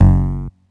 cch_bass_one_shot_promars_F#.wav